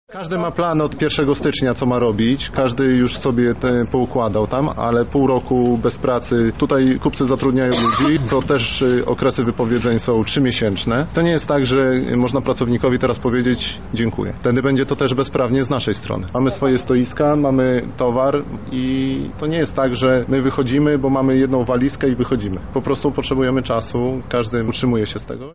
Sprzedawca
– mówi jeden ze sprzedawców.